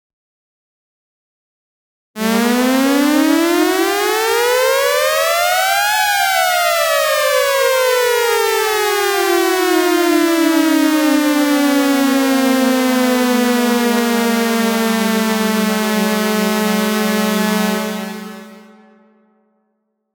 Dub Siren Sound Button - Free Download & Play